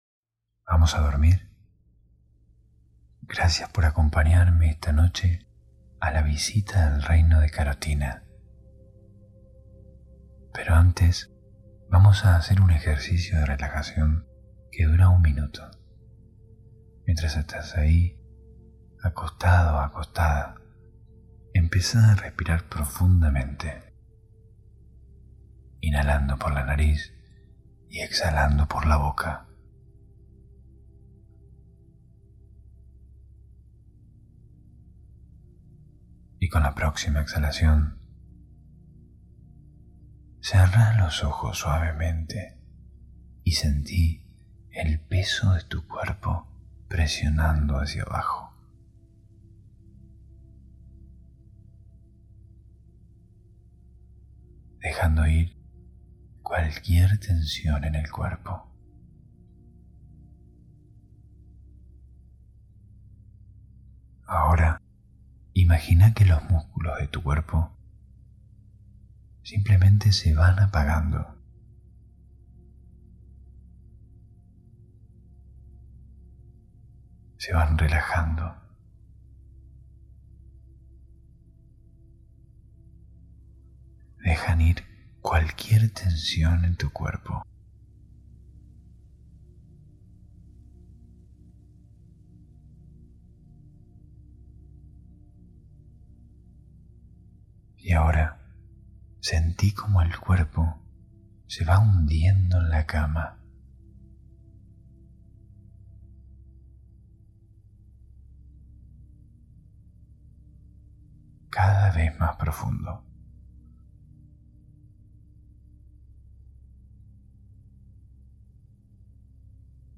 Cuentos ASMR para dormir - Luna de Carotina